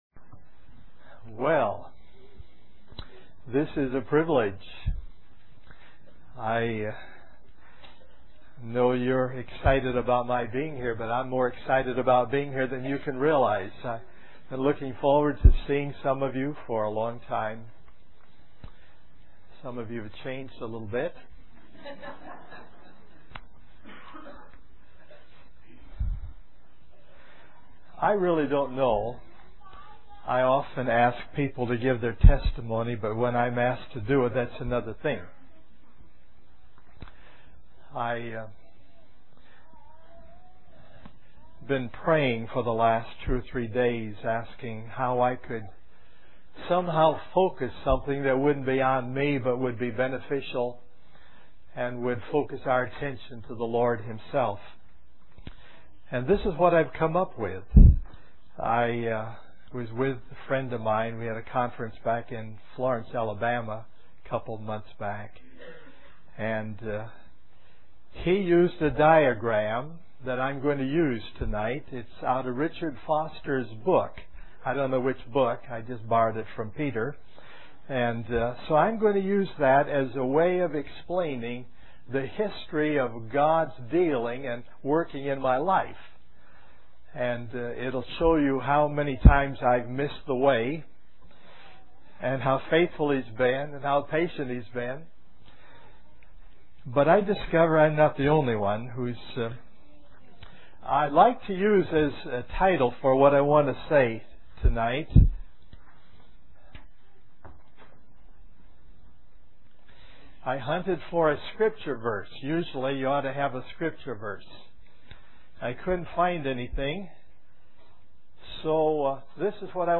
The Main Thing, Part I -- Personal Testimony
A collection of Christ focused messages published by the Christian Testimony Ministry in Richmond, VA.